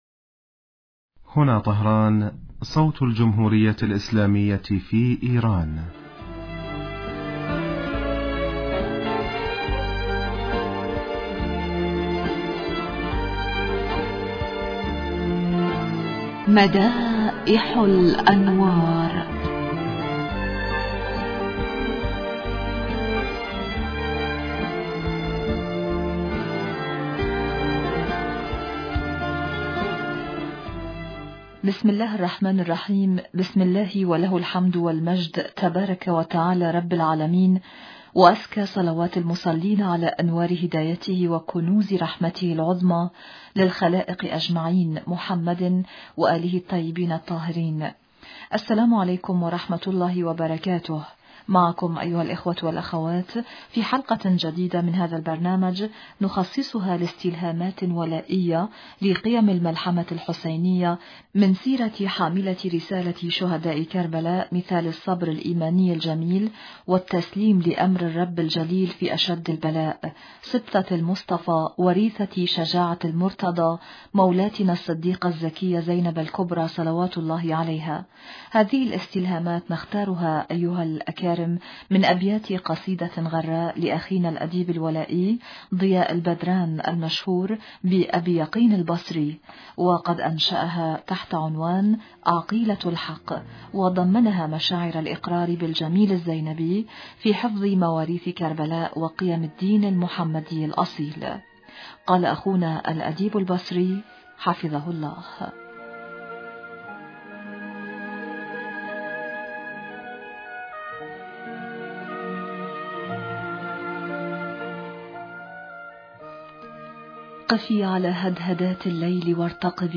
إذاعة طهران- مدائح الانوار: الحلقة 574